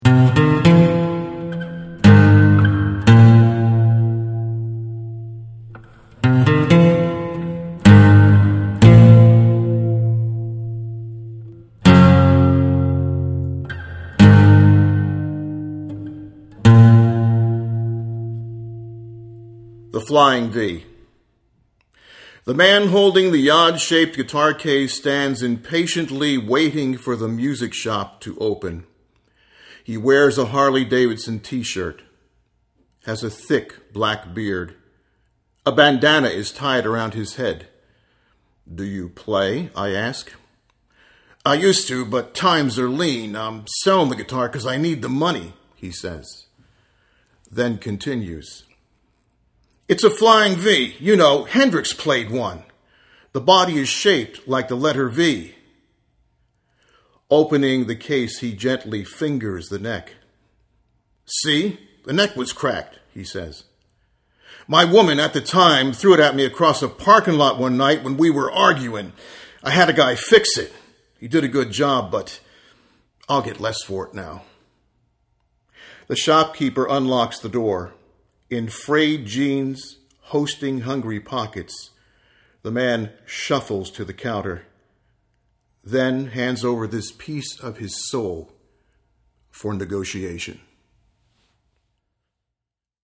Click here for a reading by the poet